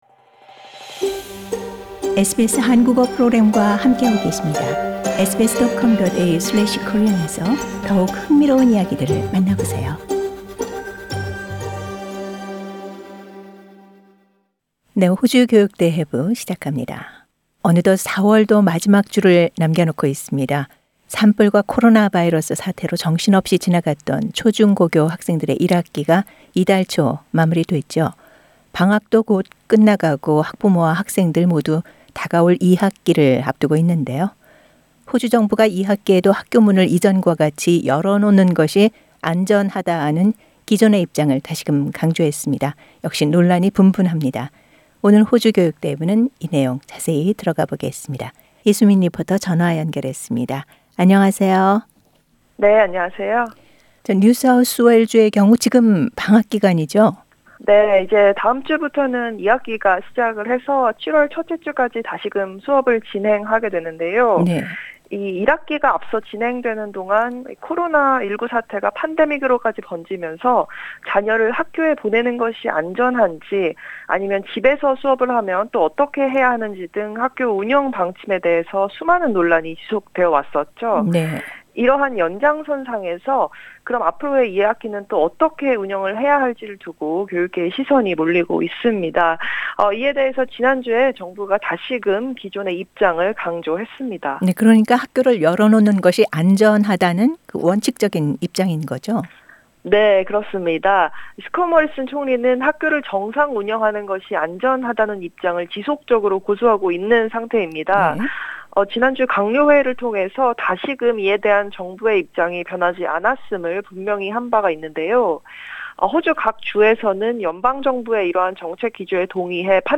리포터와 함께 이야기 나눠 보겠습니다.